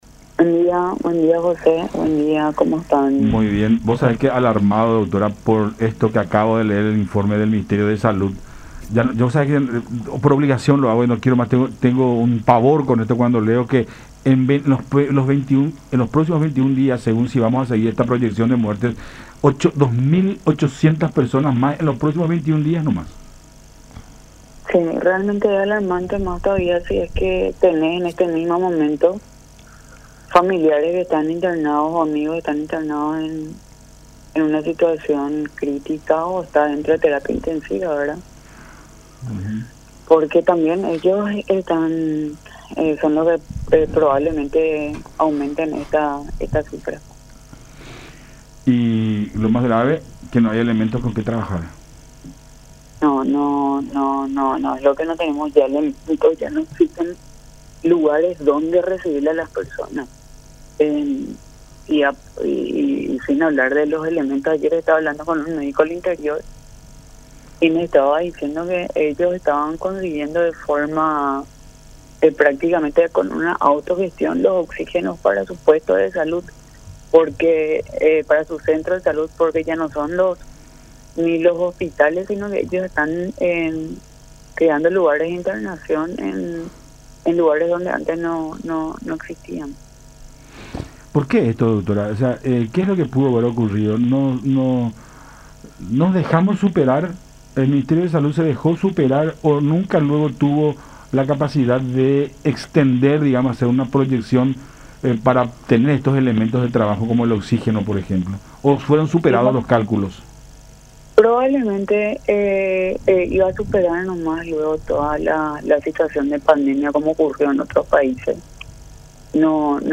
en diálogo con Cada Mañana por La Unión.